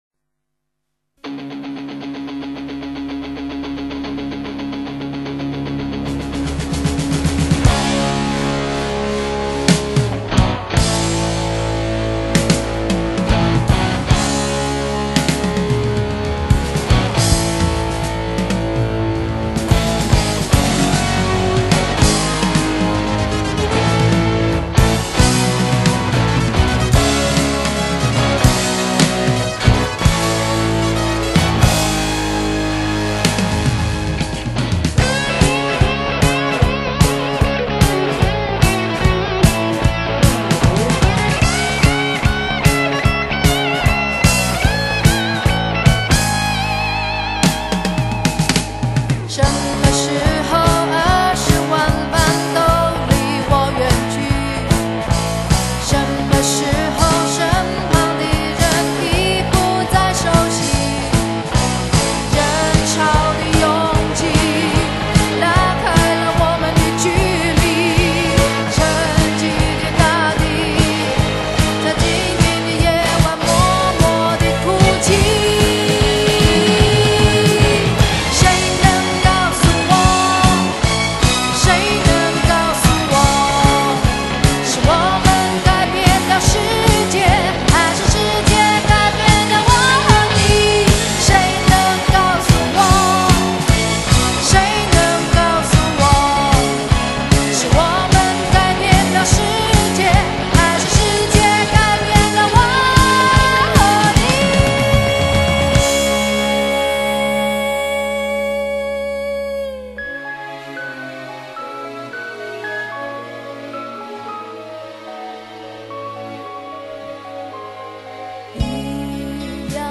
那磅礡鏗鏘配樂仿若史詩